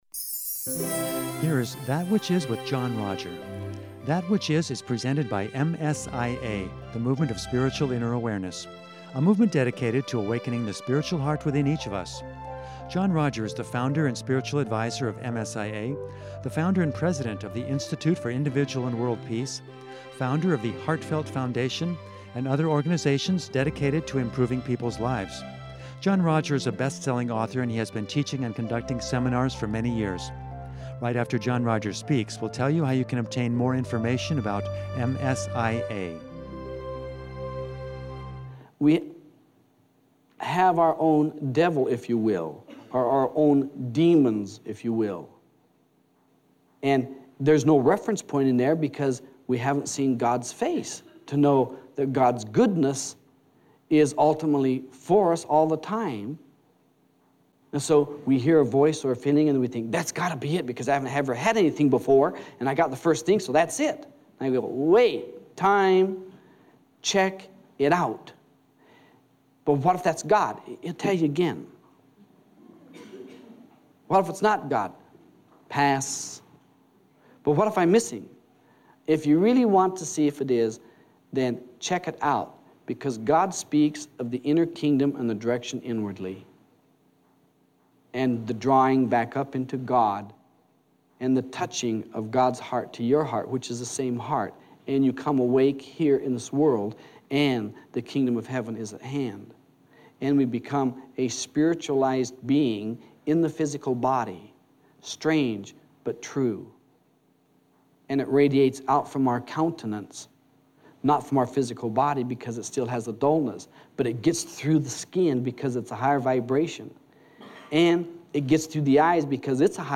If you’re looking for the unique potential inside of you, this seminar will help you identify it.